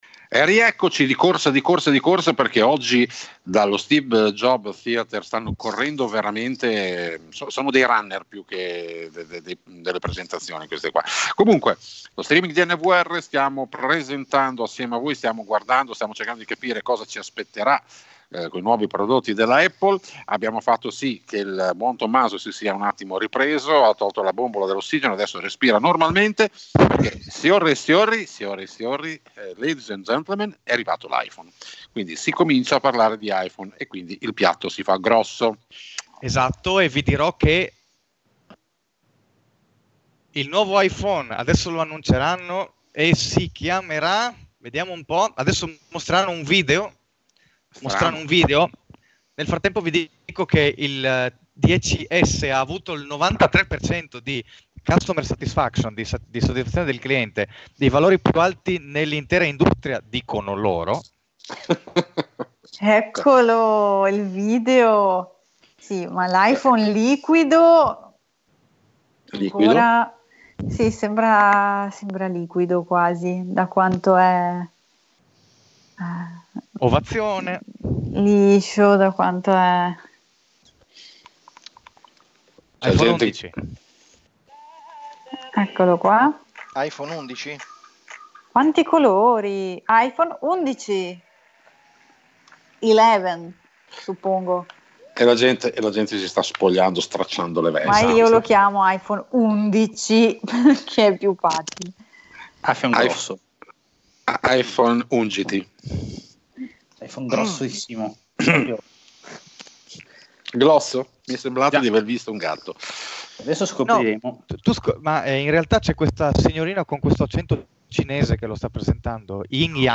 Diretta nvRadio 12 settembre: alla scoperta dell'iPhone11, parte seconda